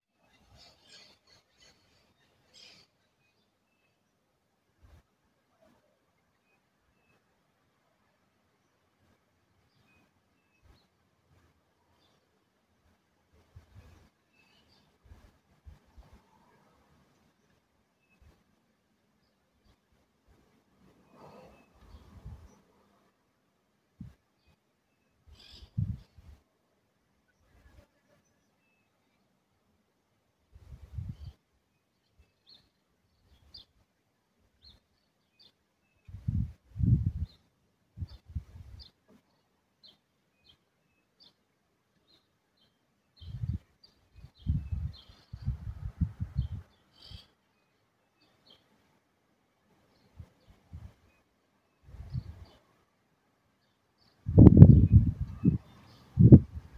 Striped Cuckoo (Tapera naevia)
Condition: Wild
Certainty: Recorded vocal